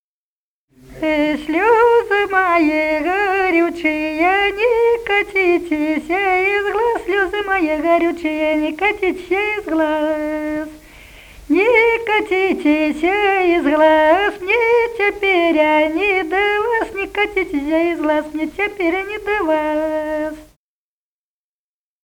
Народные песни Смоленской области
«Э, слёзы мои горючие» (плясовая).